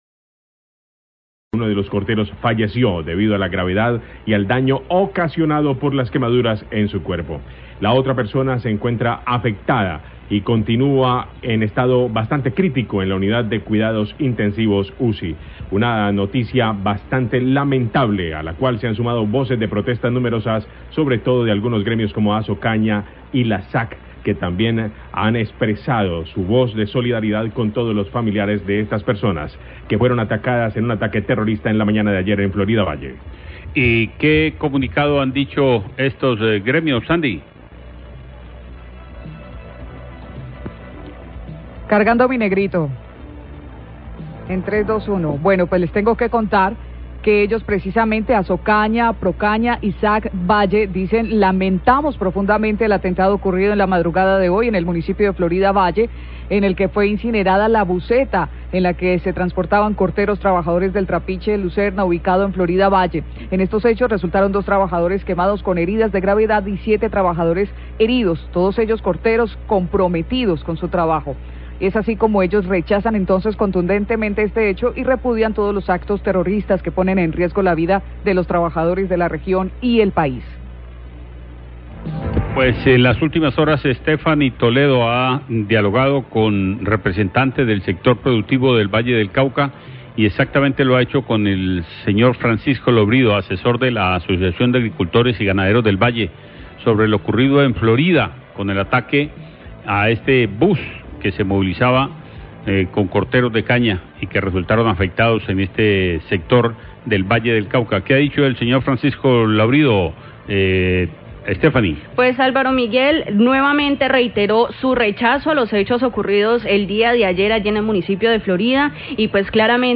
NOTICIAS DE CALIDAD